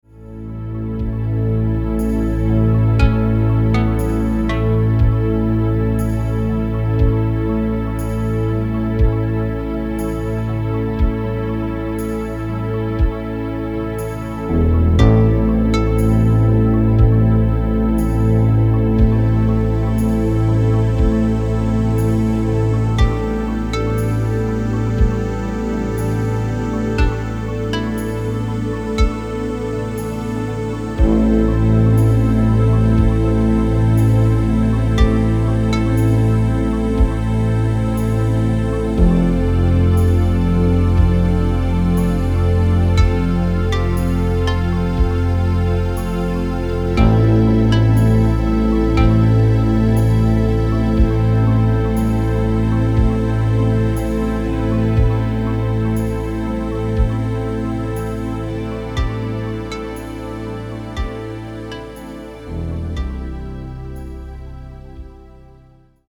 Alle Titel 60 BpM